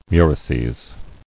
[mu·ri·ces]